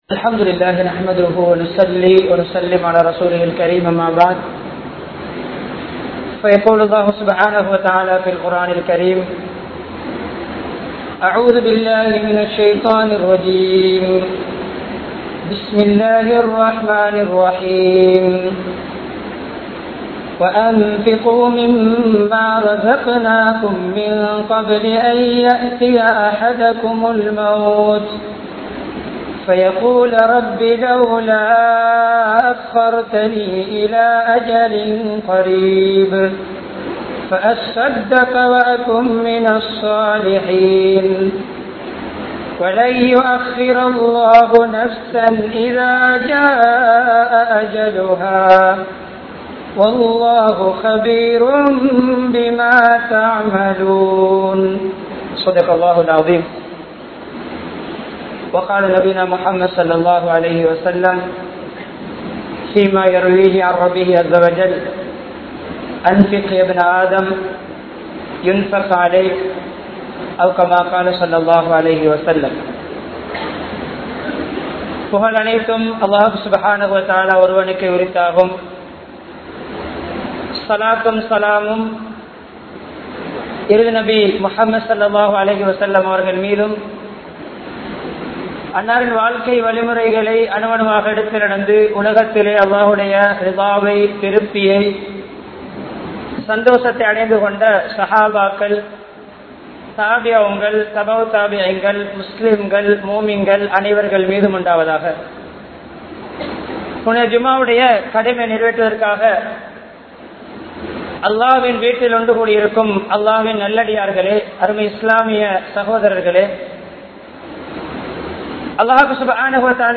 Selvantharhal & Ealaihalin Kadamaihal (செல்வந்தர்கள் & ஏழைகளின் கடமைகள்) | Audio Bayans | All Ceylon Muslim Youth Community | Addalaichenai
Gothatuwa, Jumua Masjidh